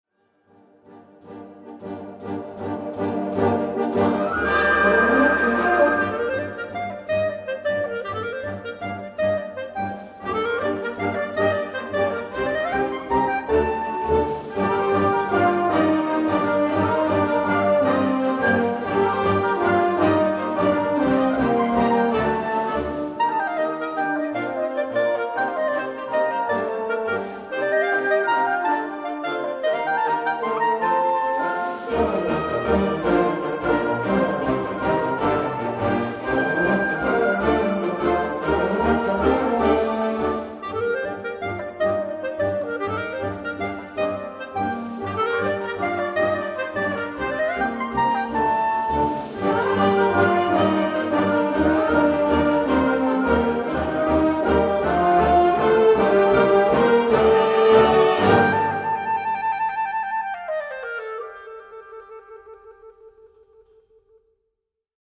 Gattung: Solo für Klarinette und Blasorchester
Besetzung: Blasorchester